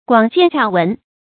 廣見洽聞 注音： ㄍㄨㄤˇ ㄐㄧㄢˋ ㄑㄧㄚˋ ㄨㄣˊ 讀音讀法： 意思解釋： 洽：廣博。看的廣，聽的多。形容見識廣博。